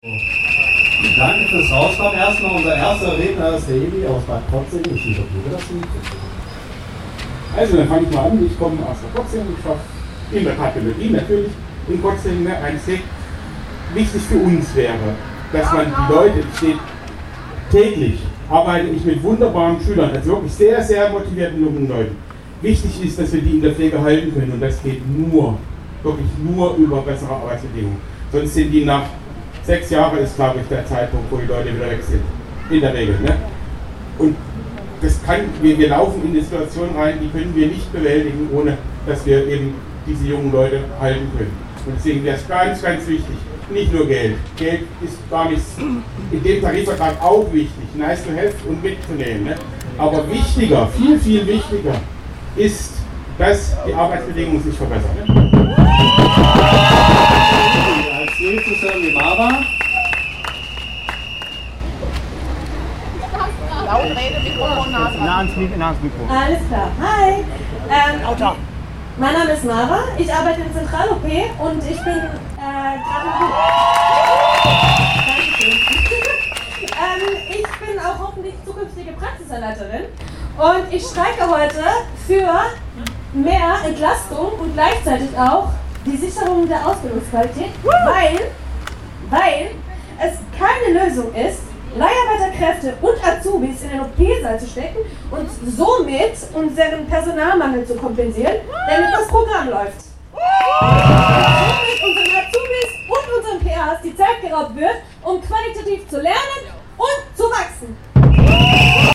In Form einer Demonstration liefen die Gewerkschaftler*innen und die streikende Belegschaft aus der Kilianstraße vor das Hauptgebäude der Veraltung des Uni-Klinikums, in die Breisacher Str. 153.
Der Aufsichtsrat tritt schließlich vor die Tür und es werden erst zwei Stimmen aus den Kliniken gehört: